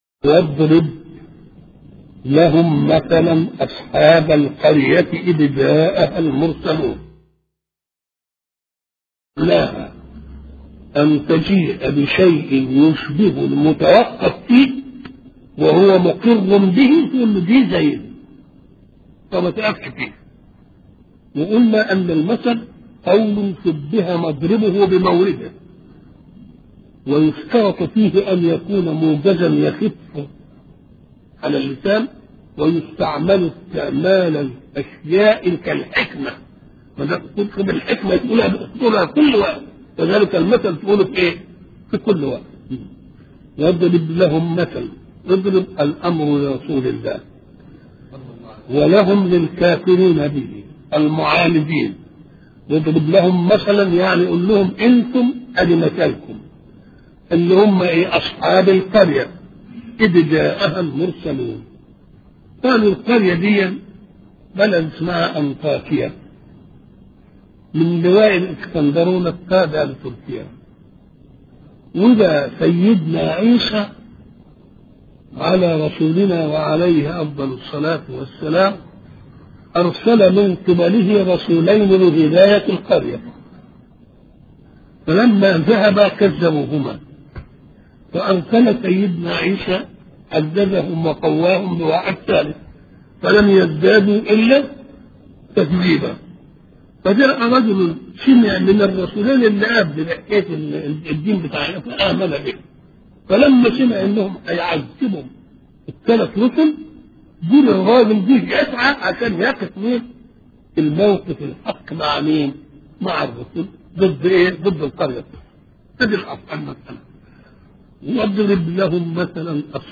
أرشيف الإسلام - أرشيف صوتي لدروس وخطب ومحاضرات الشيخ محمد متولي الشعراوي